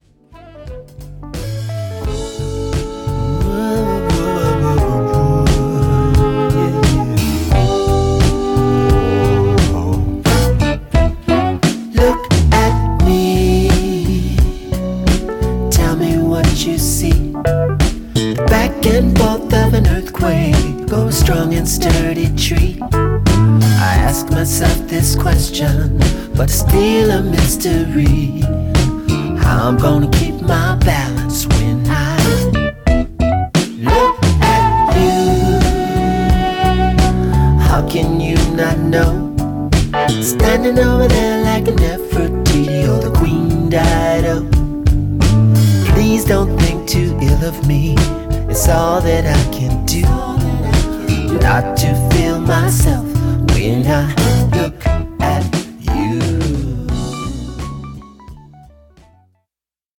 ホーム ｜ SOUL / FUNK / RARE GROOVE / DISCO > SOUL
’70年代ブラック・ミュージックの良質なエッセンスがストレートに盛り込まれた好曲が揃った